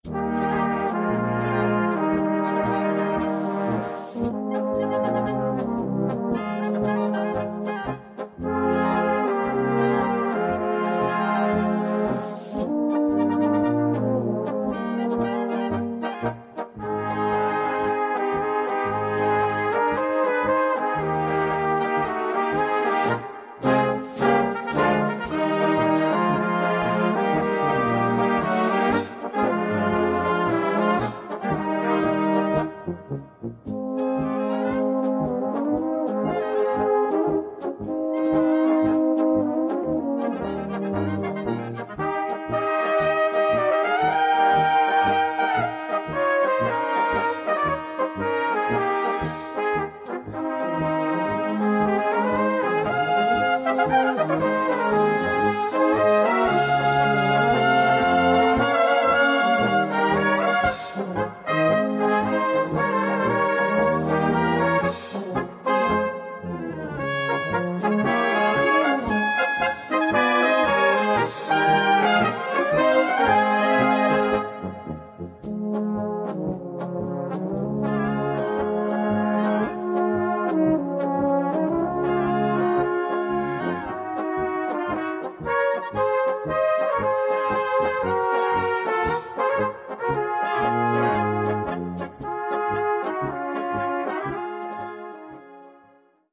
Polka-Medley
Gattung: Medley
4:23 Minuten Besetzung: Blasorchester Zu hören auf